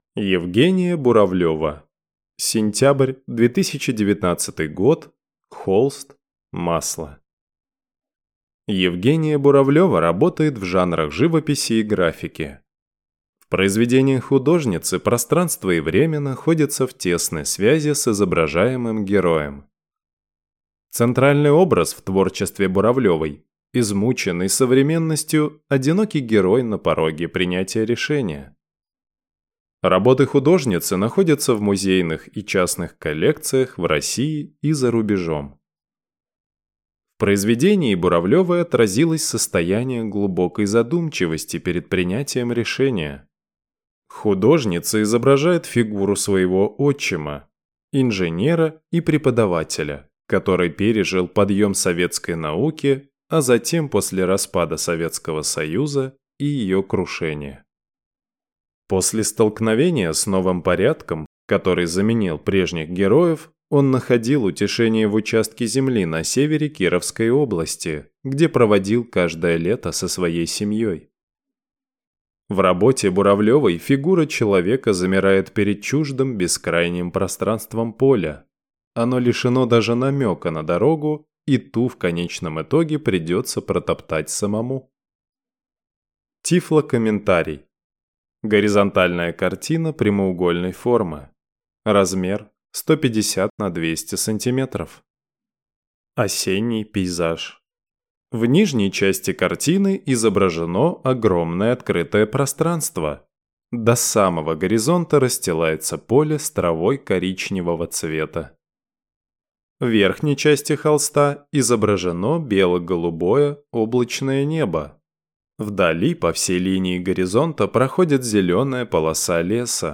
Тифлокомментарий к картине Евгении Буравлевой "Сентябрь"